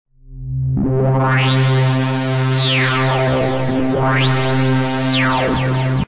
ですから音の変化がよりスムーズになります。
♪カット・オフがスムーズに変化するようになった音♪(mp3)